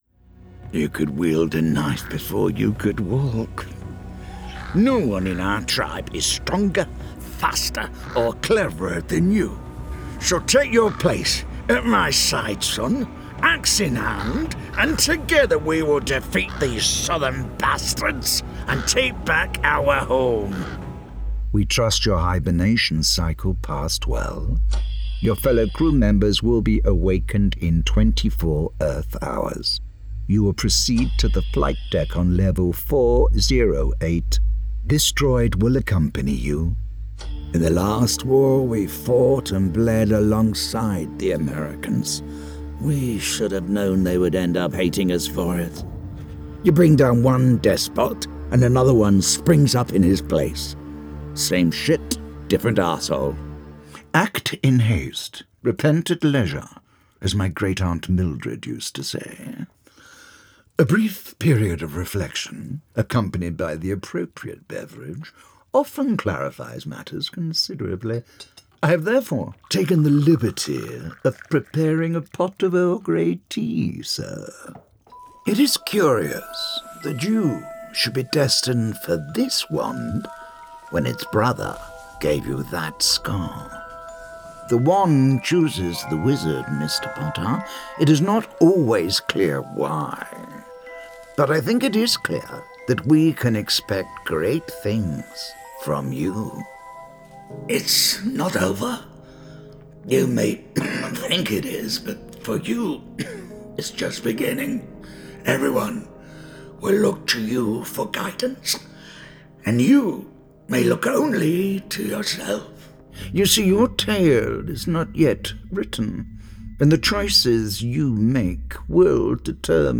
Video Game Showreel
The ultimate deep, dark, gravelly British voice, perfect for anything from dramatic and sincere to menacing and sinister. Mature, paternal, and reassuring, with a hint of thespian flair.
Male
Neutral British
Gravelly
Smooth